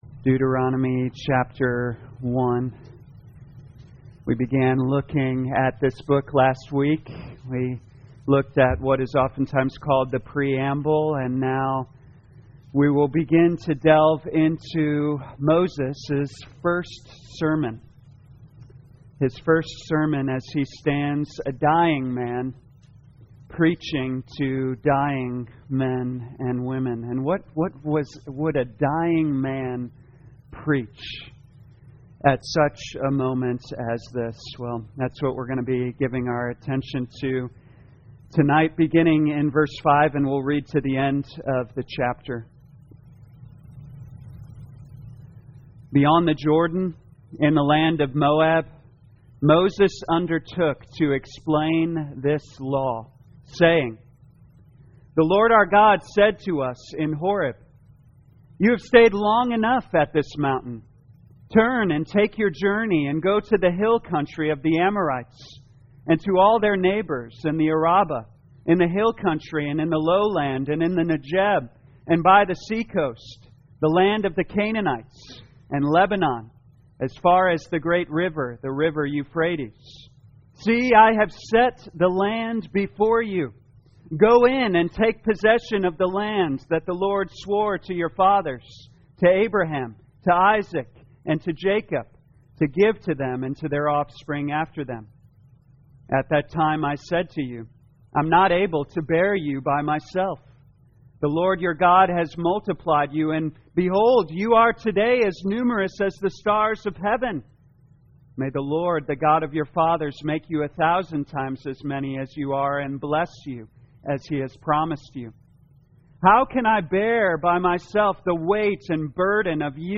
2021 Deuteronomy The Law Evening Service Download